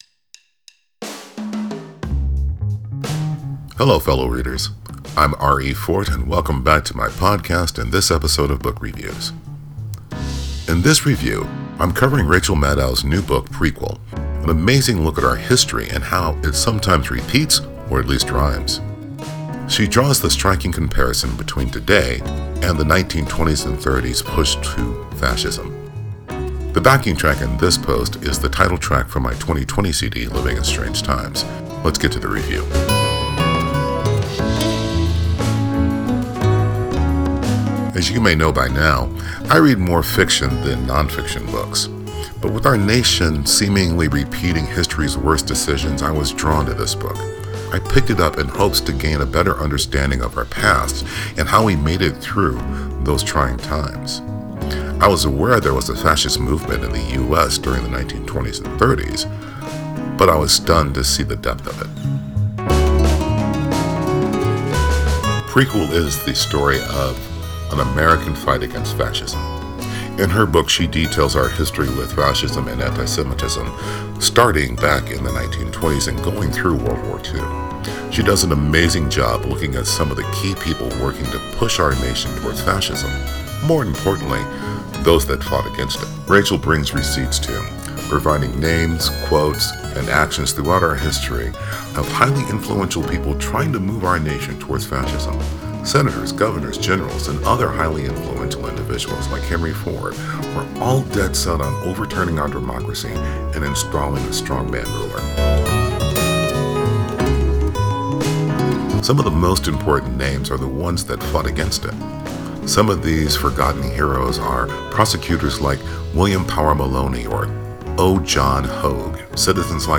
Book Review: Prequel